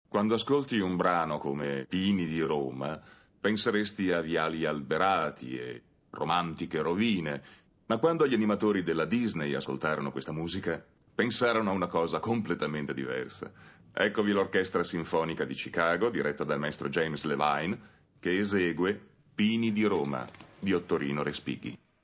voce di Roberto Alpi nel film d'animazione "Fantasia 2000", in cui doppia Itzhak Perlman.